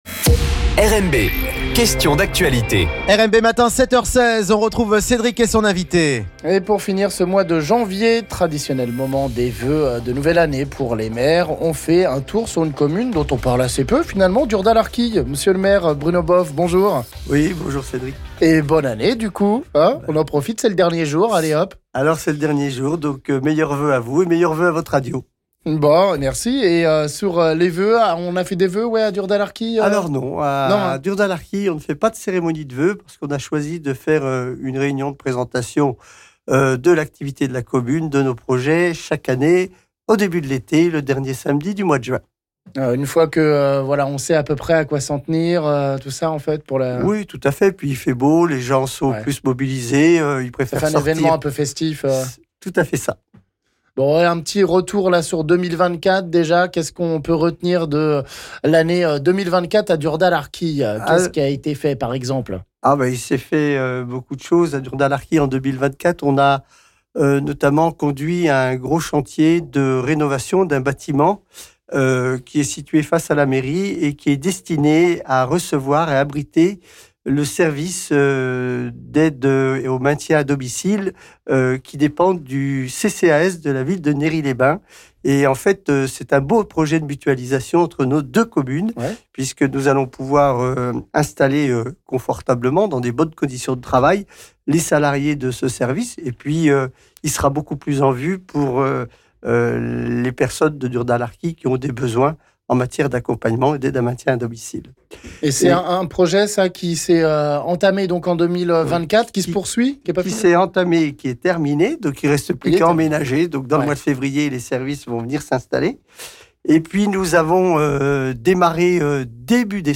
Bruno Bove, maire de Durdat-Larequille nous fait le point sur l'actu de sa commune - L'Invit� du Jour